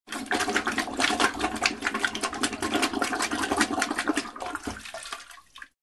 Звук интенсивного поноса у человека